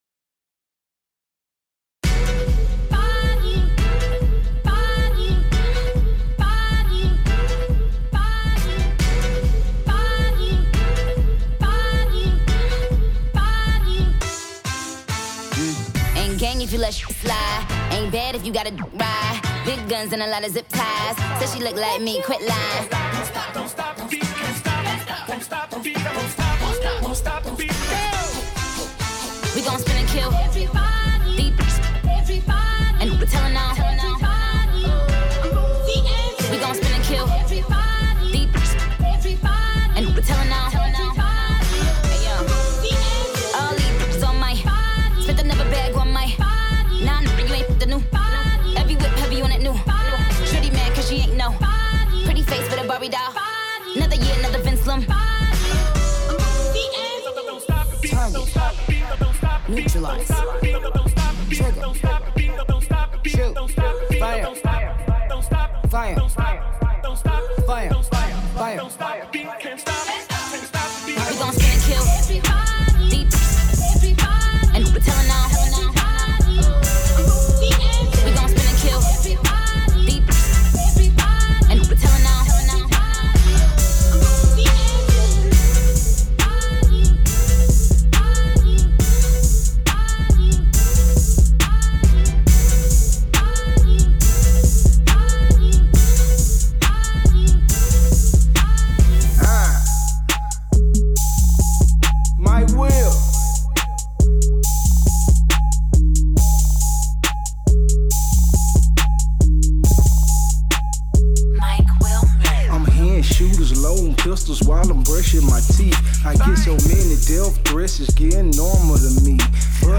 Live Wedding Mix
A live wedding mix